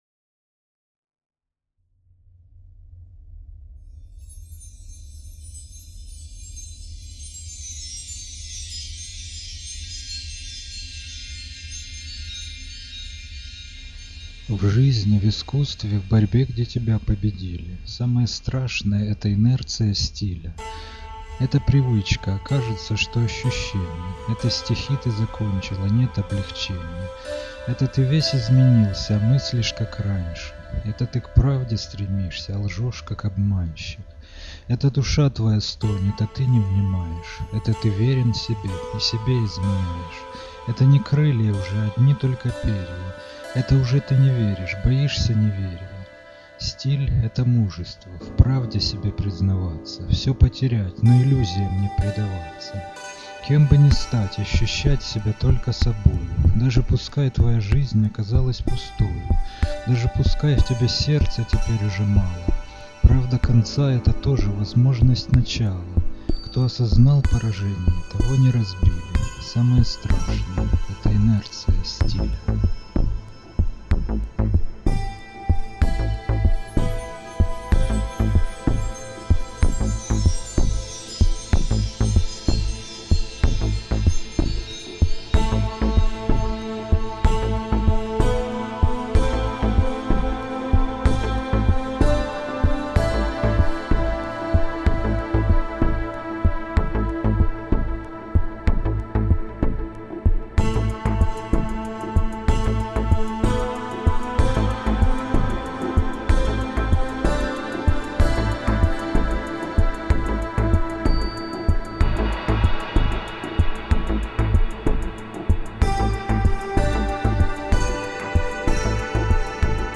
чит.